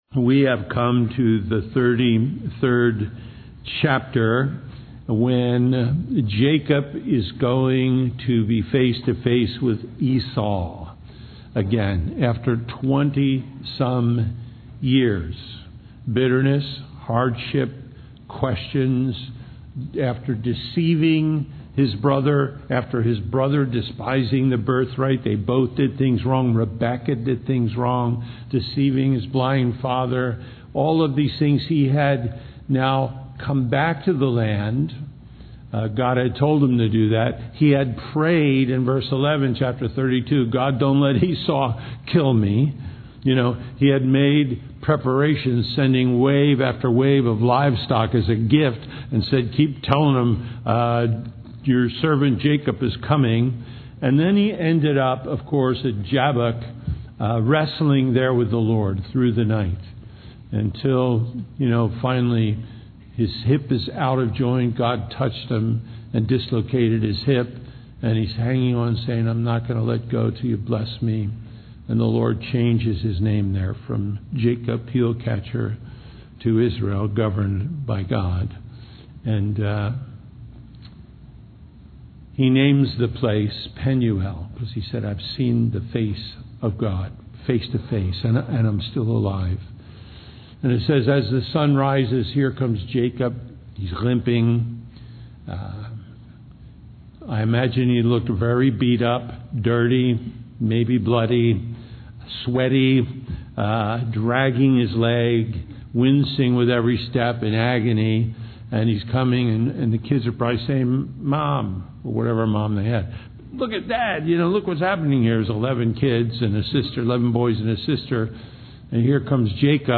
Genesis 33:1-33:20 Reconciliation Listen Download Original Teaching Email Feedback 33 And Jacob lifted up his eyes, and looked, and, behold, Esau came, and with him four hundred men.